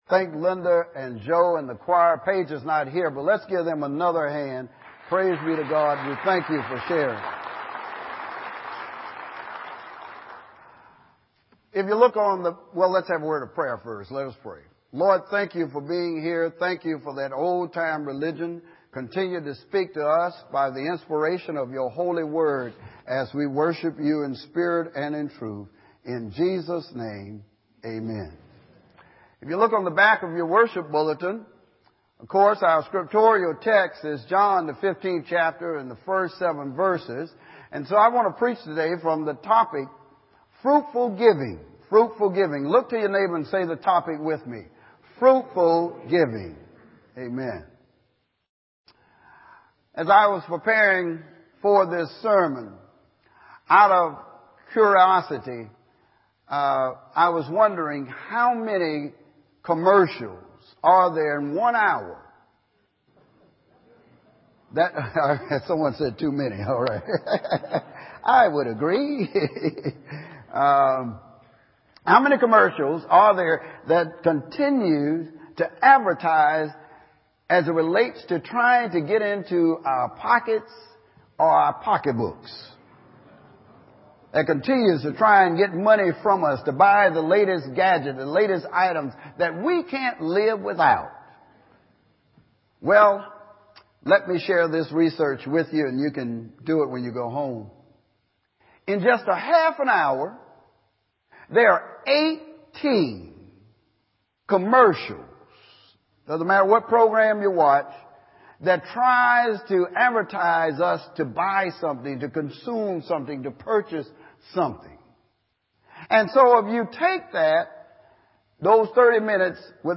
Palm Coast United Methodist Church Audio Sermons